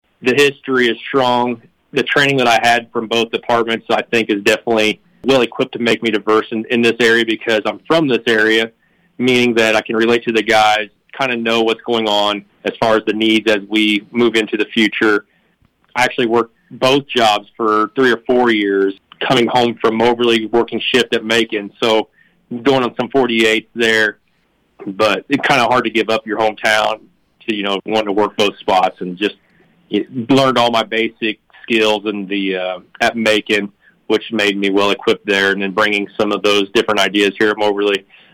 He says that strong family history helped to prepare him for his career.